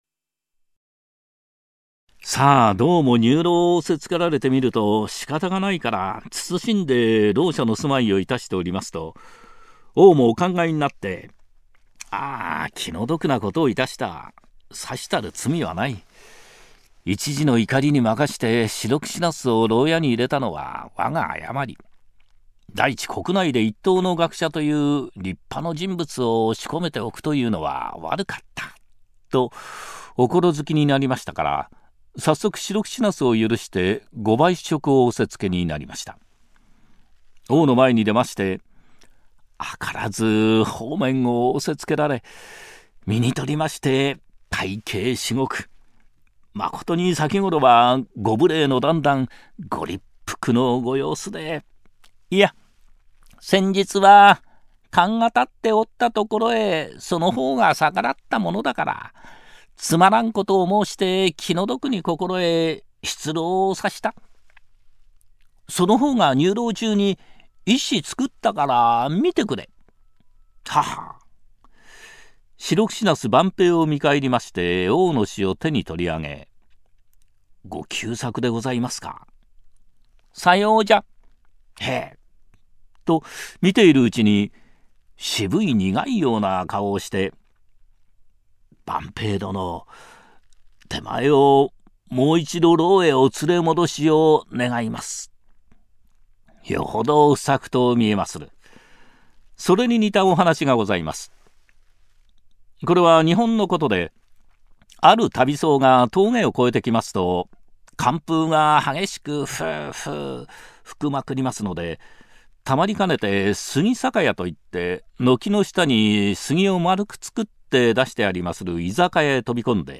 朗 読
※一部の作品には、現在において不適切と思われる表現が含まれている場合がありますが、 原作の内容を尊重し、原作通り朗読させていただいております。